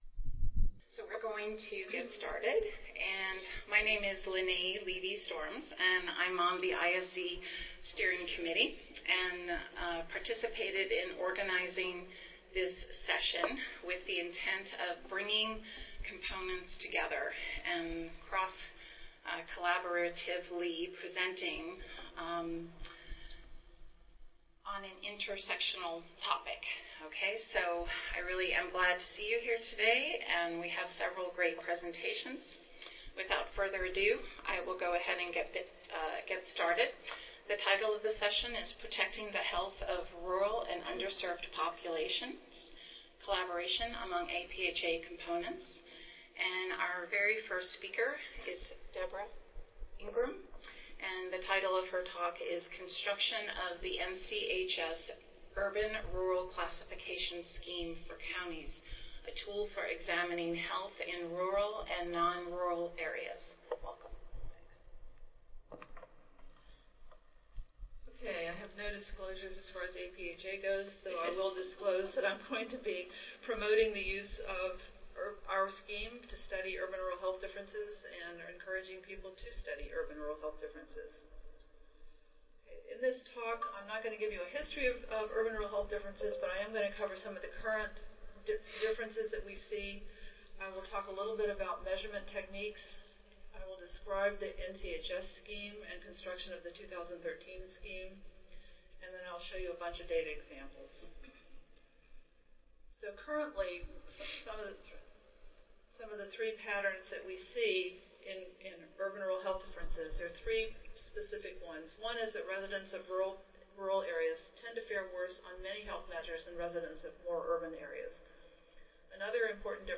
142nd APHA Annual Meeting and Exposition (November 15 - November 19, 2014): Protecting the Health of Rural and Underserved Populations � Collaboration among APHA Components
Recorded Presentation